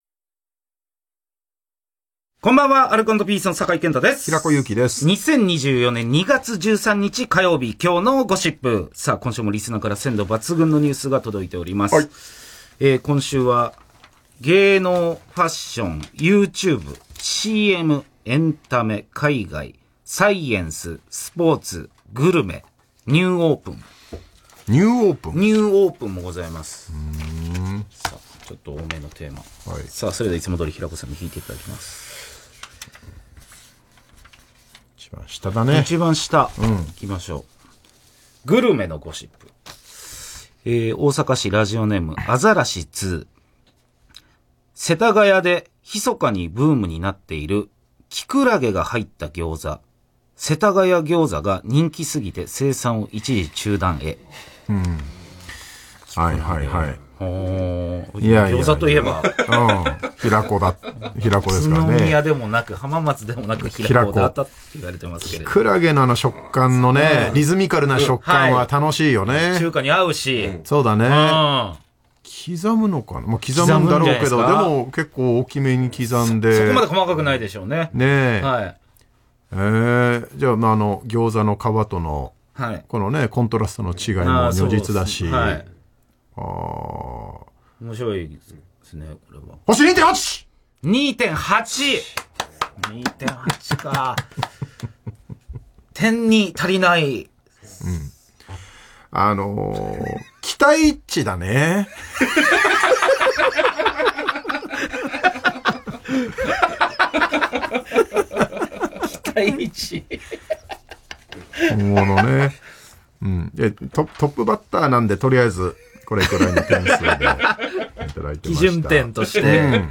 「アルコ＆ピース」は、平子祐希、酒井健太 のお笑いコンビです。